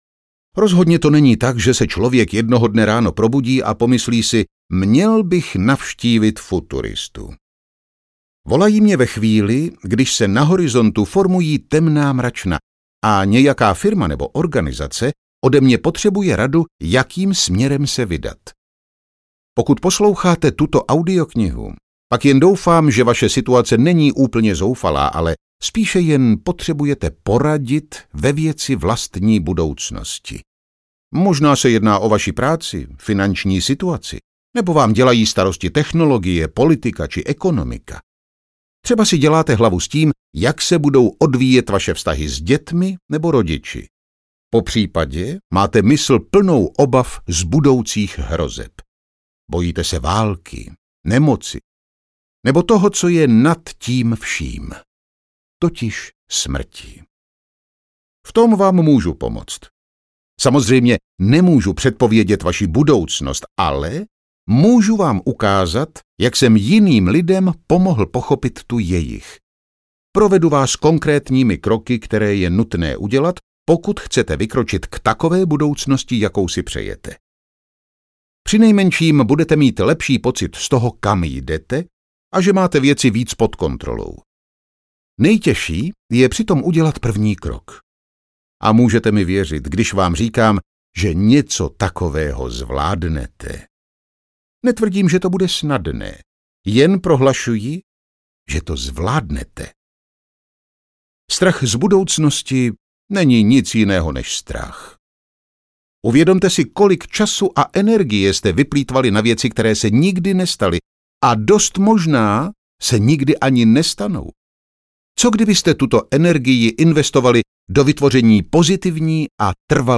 Budoucnost pod kontrolou audiokniha
Ukázka z knihy